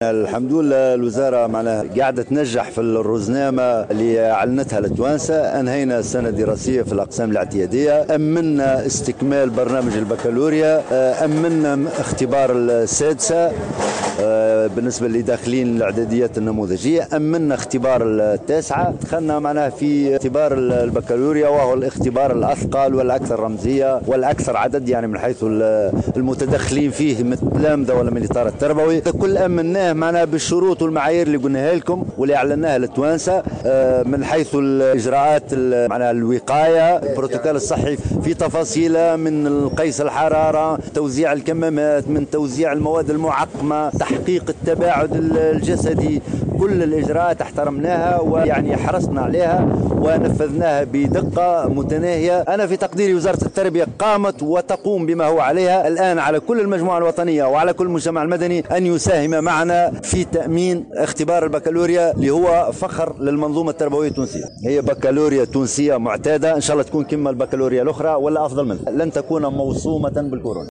واكد الحامدي في تصريح لـ"الجوهرة أف أم" أن كل الظروف والشروط متوفّرة لإنجاح هذه الدورة وسط اجراءات الوقاية ضد فيروس "كورونا" لتأمين اجراء الامتحان في أحسن الظروف. كما شدّد الوزير على استعدادات الوزارة للتصدي لظاهرة الغشّ في امتحان الباكالوريا.